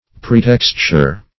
Pretexture \Pre*tex"ture\ (?; 135), n.